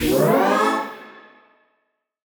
FR_T-PAD[up]-A.wav